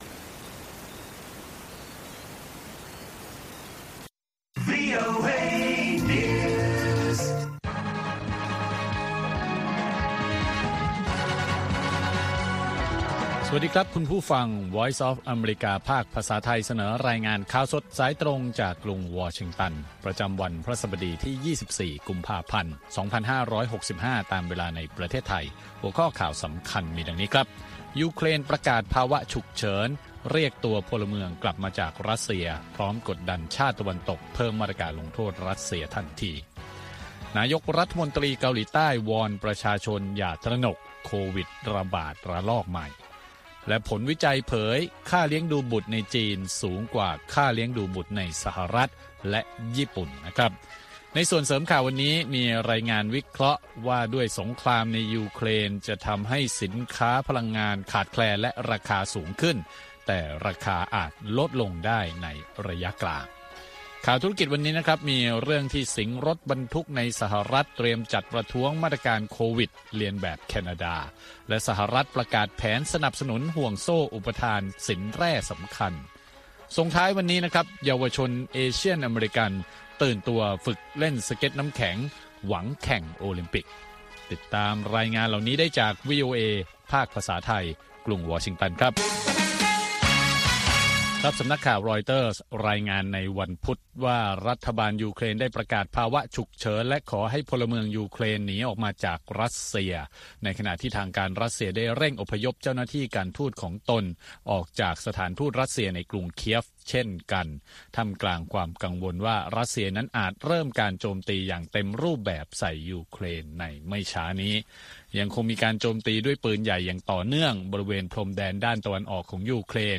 ข่าวสดสายตรงจากวีโอเอ ภาคภาษาไทย 6:30 – 7:00 น. ประจำวันพฤหัสบดีที่ 24 กุมภาพันธ์ 2565 ตามเวลาในประเทศไทย